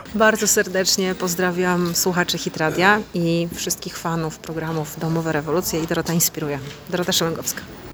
Pozdrowiła również Wszystkich  słuchaczy RADIA HIT.
pozdrowienia-szelagowska.wav